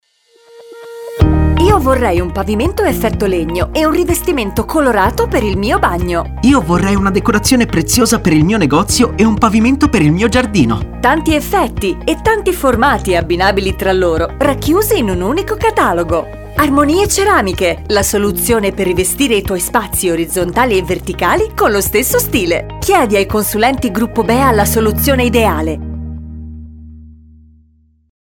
ArmonieCeramiche2vociSoggettoA.mp3